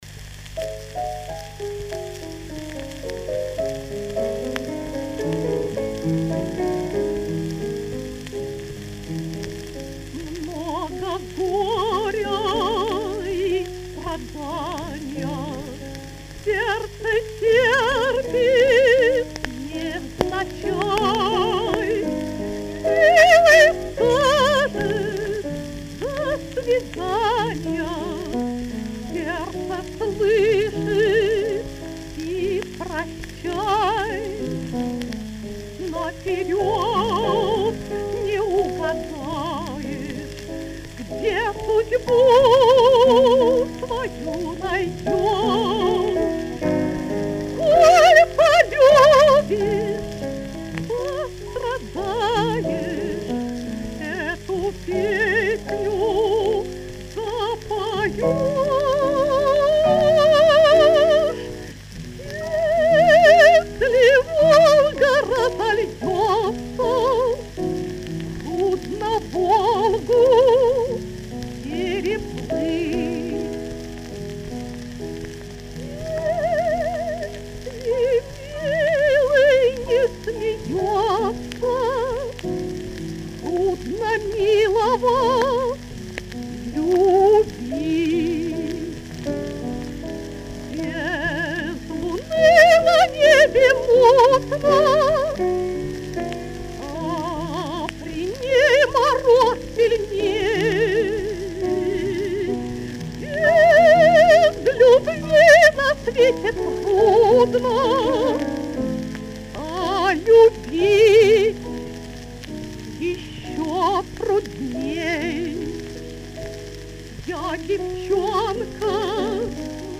рояль